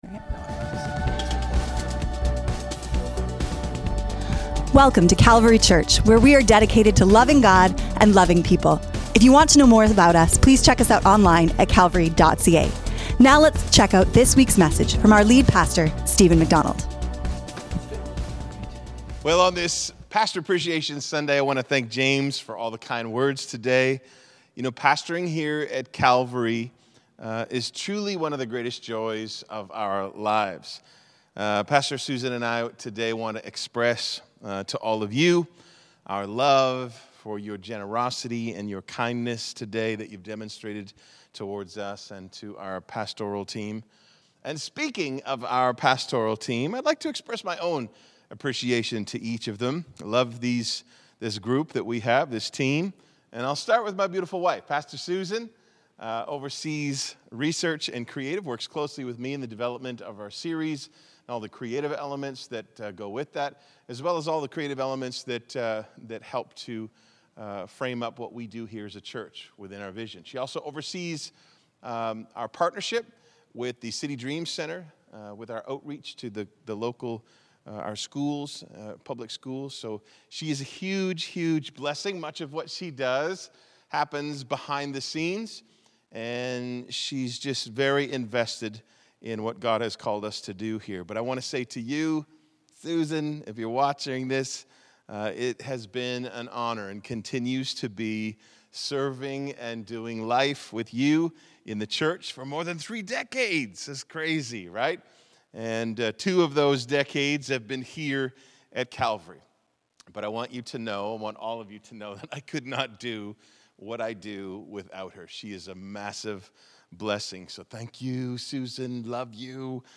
2024 Current Sermon What Is Spiritual Warfare?